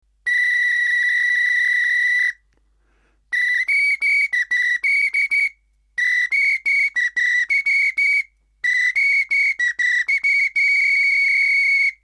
ソフトホイッスル